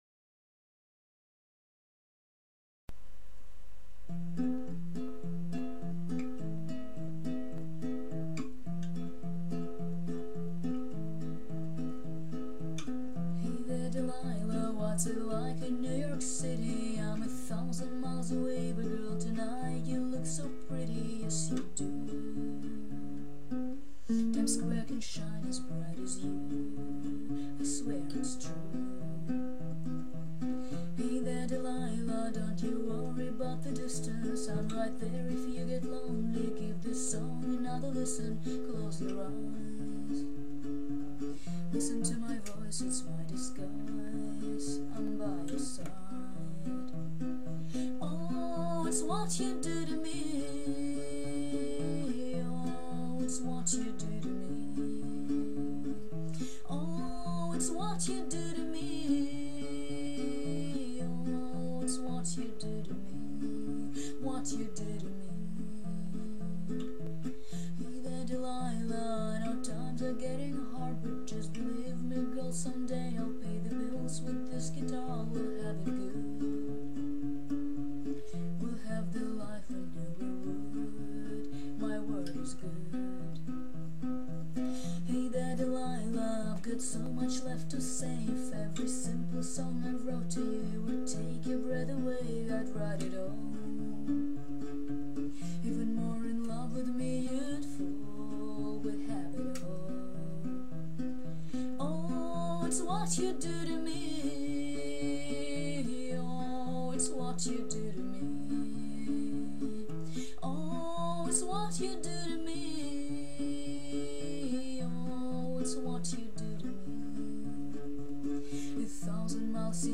different key smile